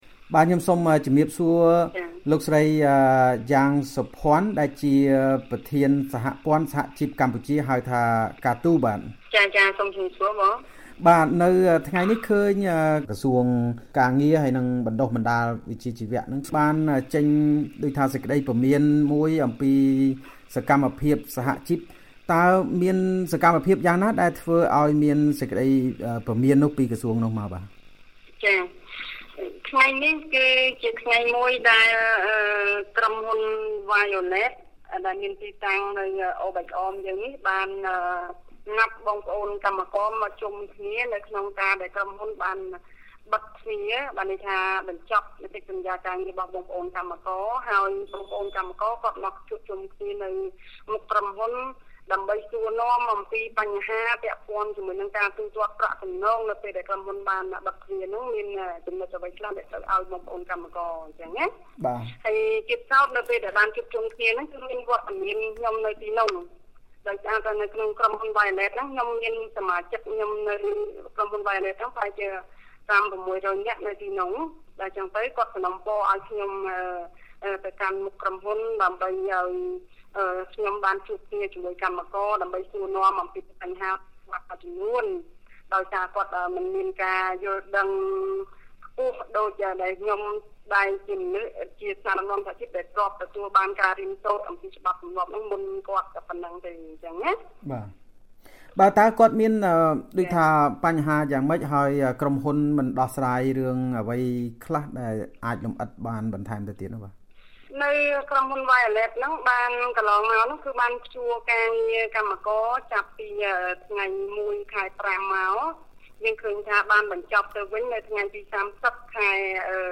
បទសម្ភាសន៍ VOA៖ មេដឹកនាំសហជីពរងការព្រមានដោយសារជួយដោះស្រាយបញ្ហាកម្មករកាត់ដេរ